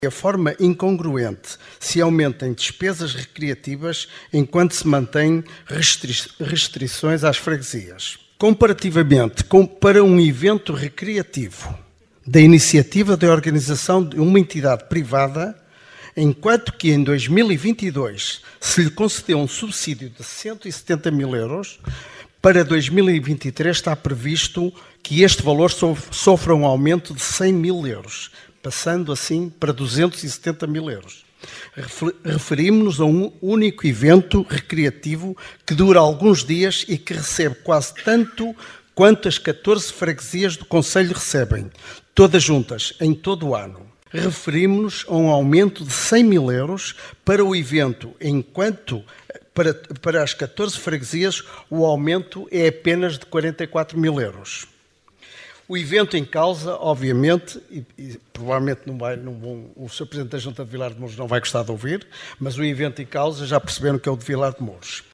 Excertos da última assembleia municipal de Caminha, de 16 de dezembro de 2022.